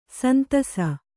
♪ santasa